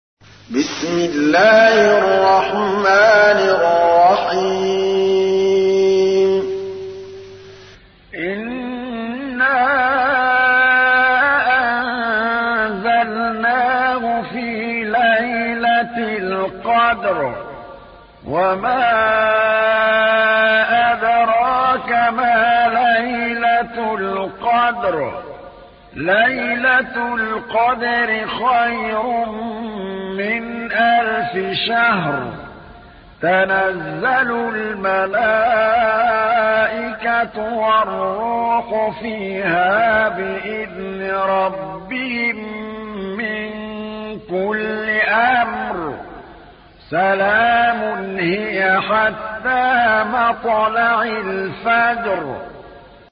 تحميل : 97. سورة القدر / القارئ محمود الطبلاوي / القرآن الكريم / موقع يا حسين